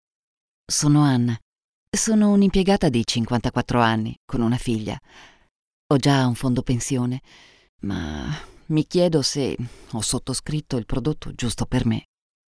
Female
Yng Adult (18-29), Adult (30-50)
Natural Speak
1002natural_speak.mp3